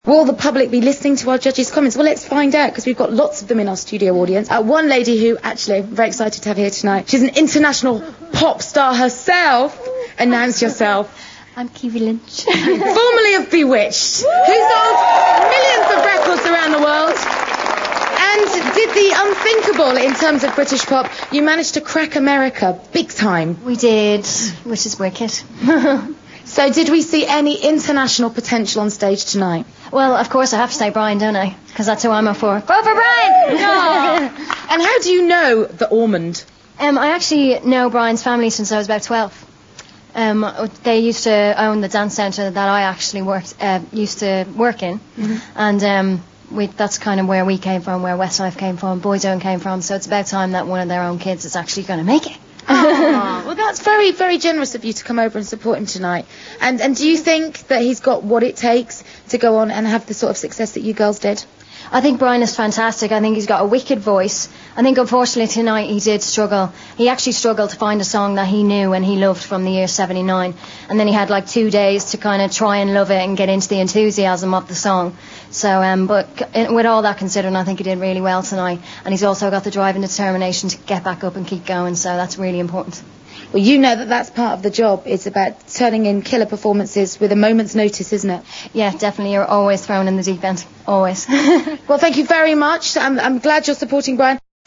Keavy appeared in the audience for this show. Interviewed by Kate Thornton.